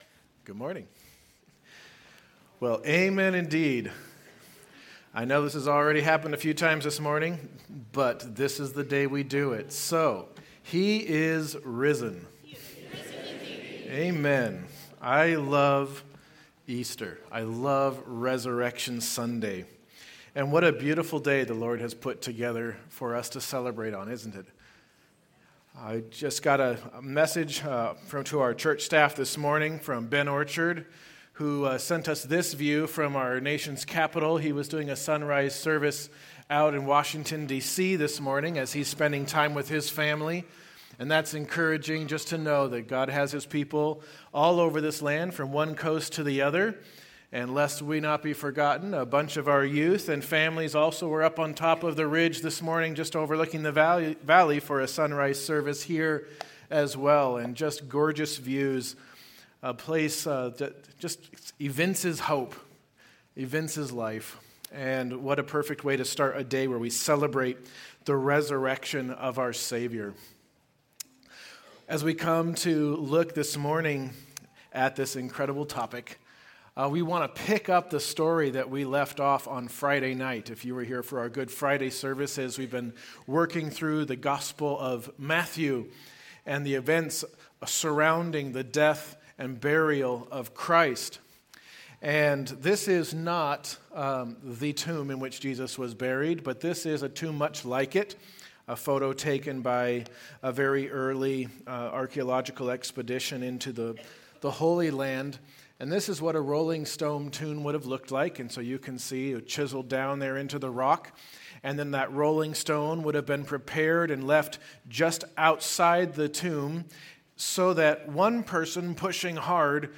April 5’s Sunday service livestream, bulletin/sermon notes, the online Connection Card, and playlists of Sunday’s music (Spotify and YouTube).